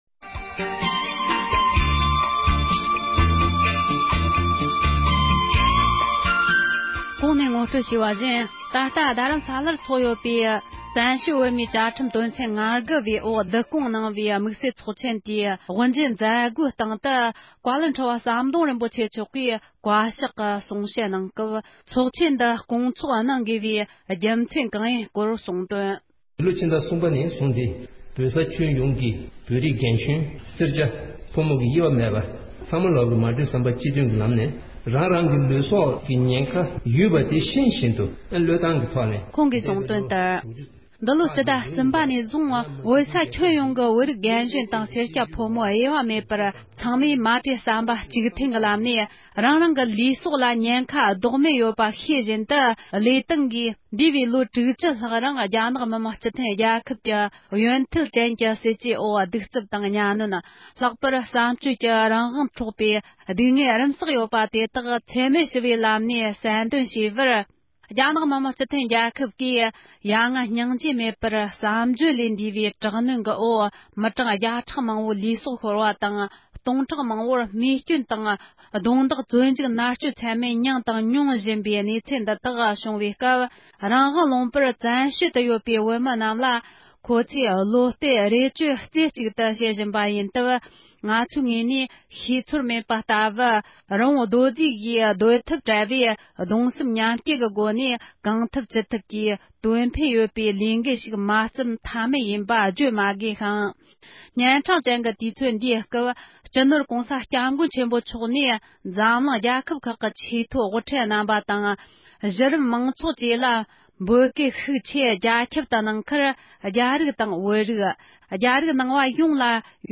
བཞུགས་སྒར་དུ་ཟླ་འདིའི་ཚེས་བཅུ་བདུན་ཉིན་བོད་དོན་དམིཌ་བསལ་ཚོཌ་ཆེན་དབུ་འབྱེད་གནང་སྐབས་བཀའ་ཤག་གི་གསུང་བཤད
དམིཌ་བསལ་ཚོཌ་དབུ་འབྱེད་སྐབས་བཀའ་ཤག་གི་གསུང་བཤད།